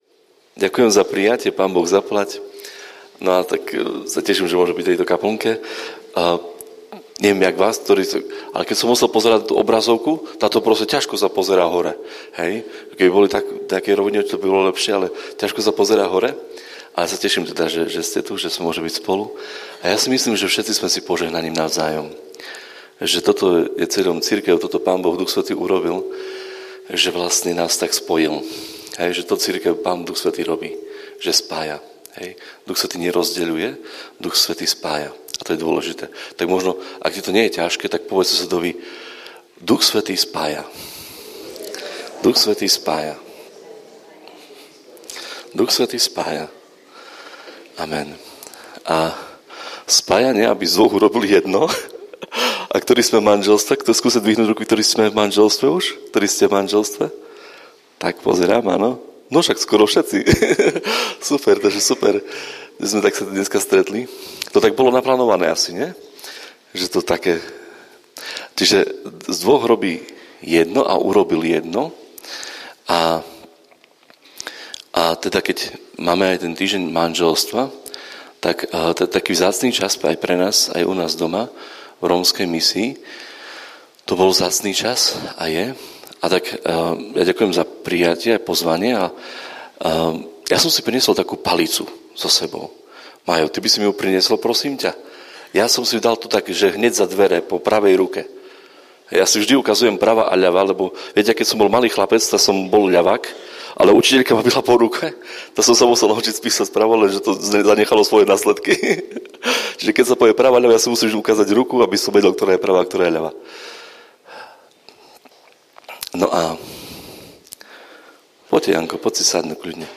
Záznam zo stretnutia Večer chvál v Bardejove, február 2025.